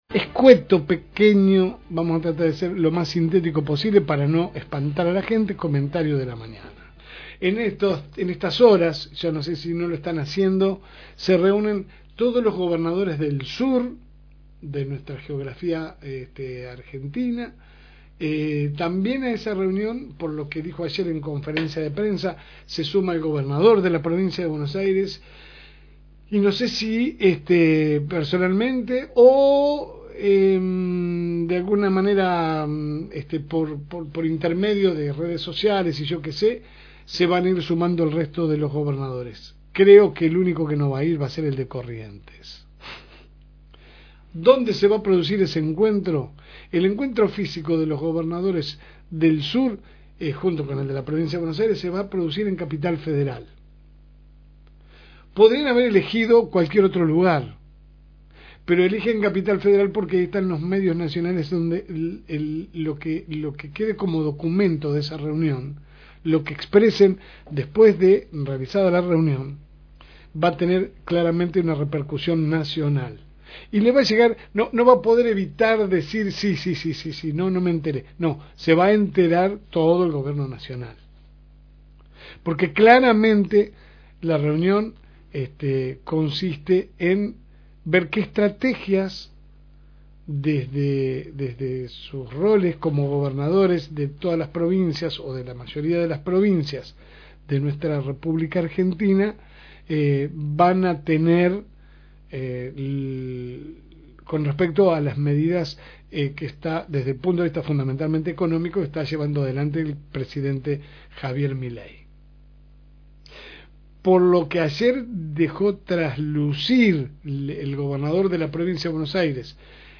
Aquí abajo el audio de su editorial